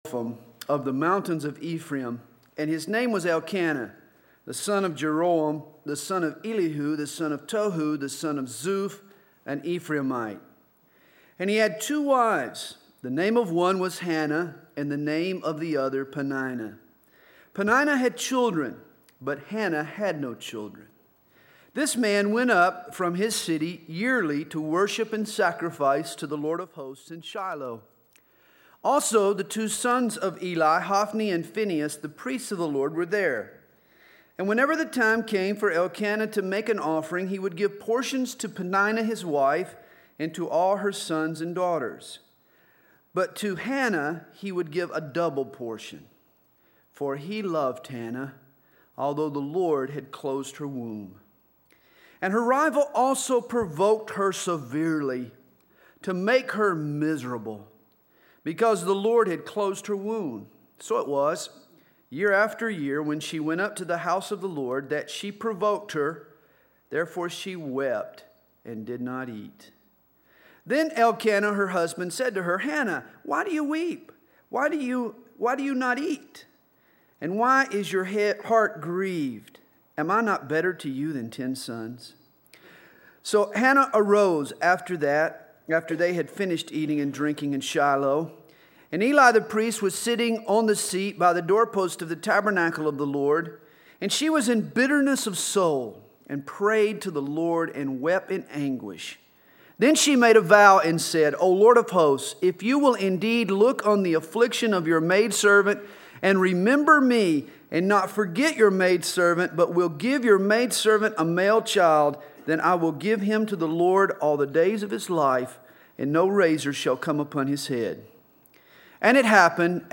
2002 DSPC Conference: Pastors & Leaders Date